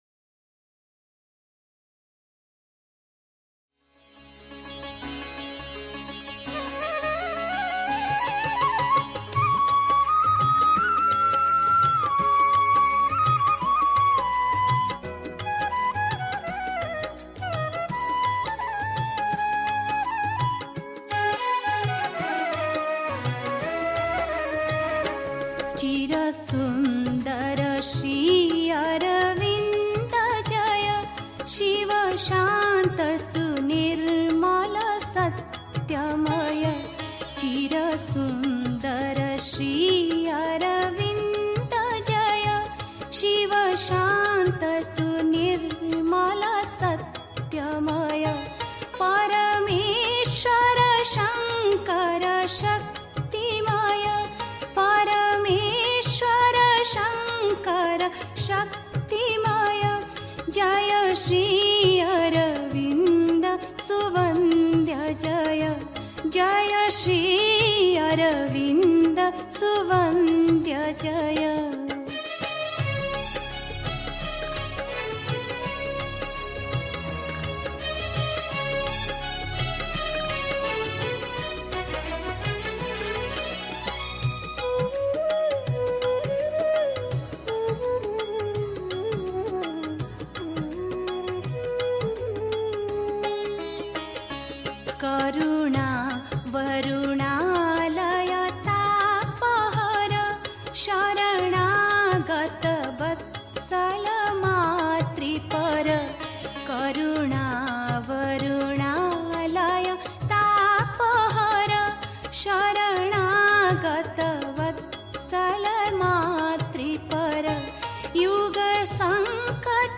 1. Einstimmung mit Musik. 2. Der Wanderstab muss aus dem Feuer des Glaubens bestehen (Sri Aurobindo, CWSA, Vol. 29, pp. 92-94) 3. Zwölf Minuten Stille.